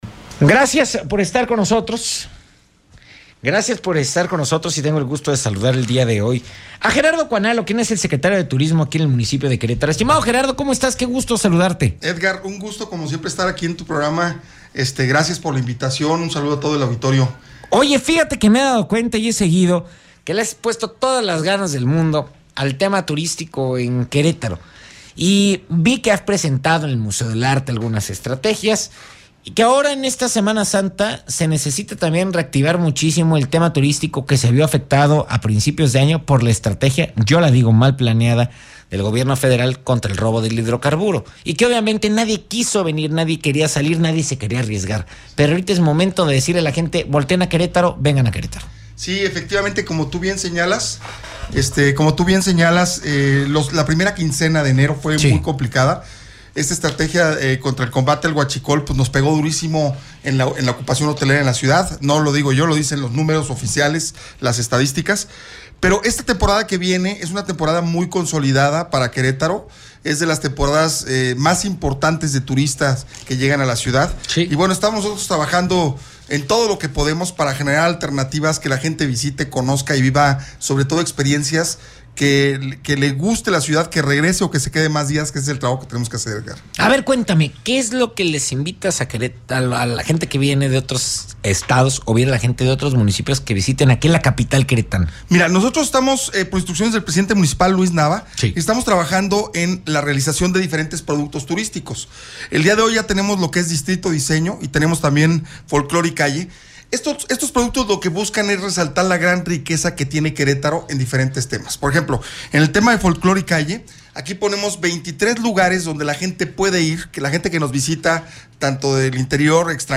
Entrevista con el Secretario de Turismo del Municipio de Querétaro, Gerardo Cuanalo.